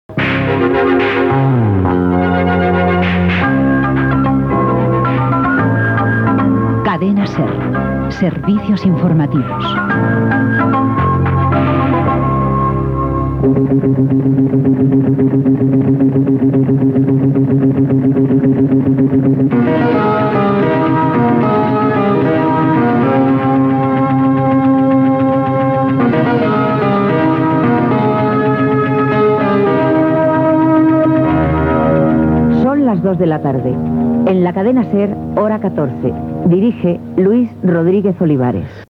Careta del programa